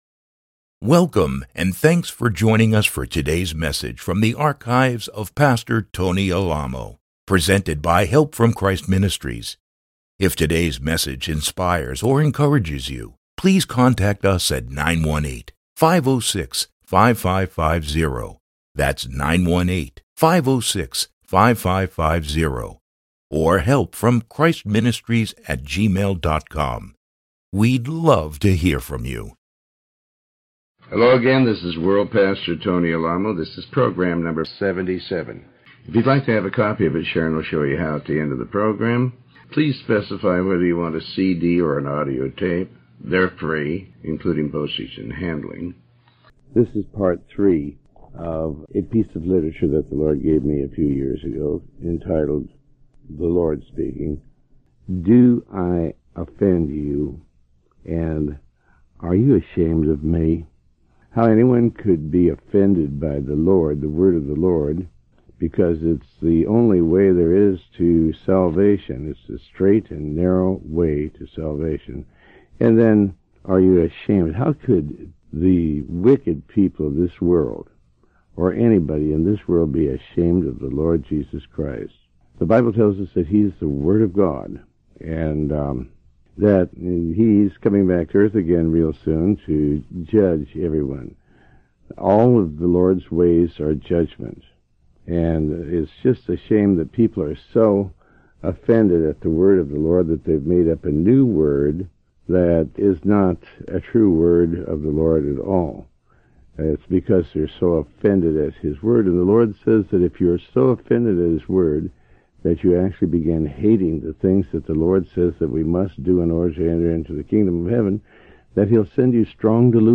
Sermon 77A